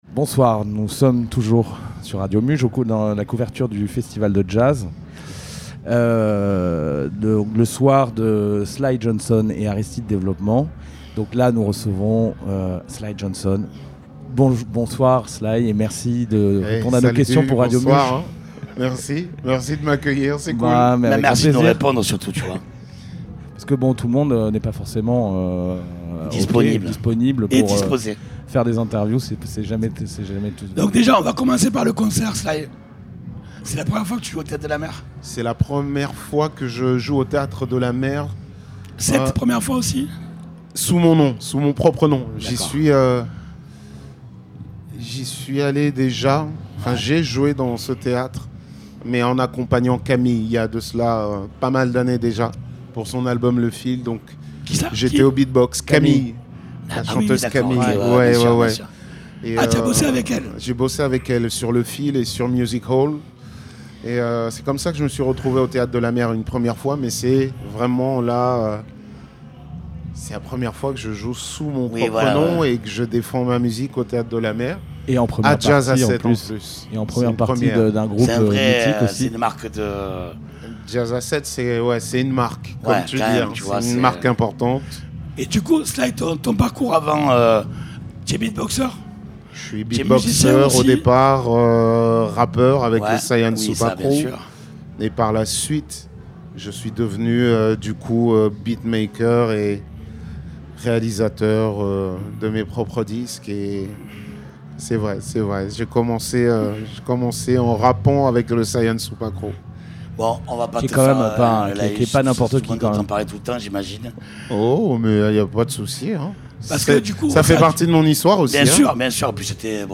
ITW de Sly Johnson réalisée au Théâtre de la Mer après son concert lors du Festival de Jazz à Sète le lundi 17 Juillet.